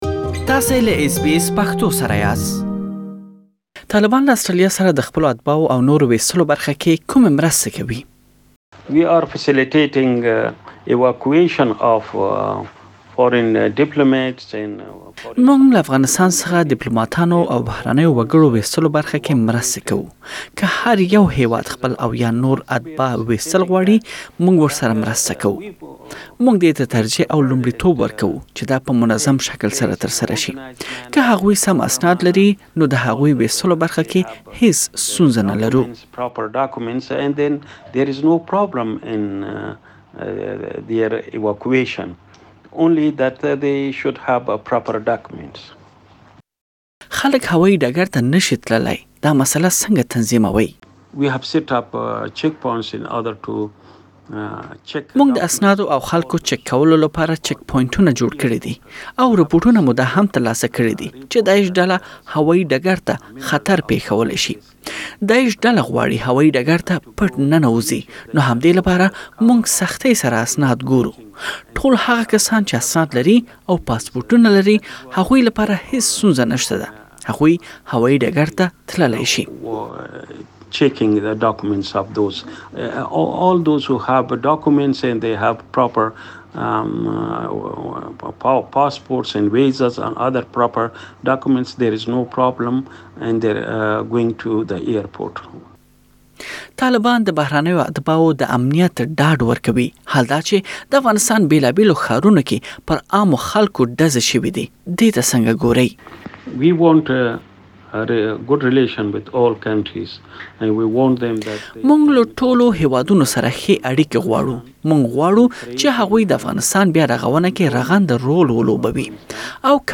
طالبانو وياند سهيل شاهين سره ځانګړې مرکه: نړيوال بايد افغانستان بيا رغونه کې برخه واخلي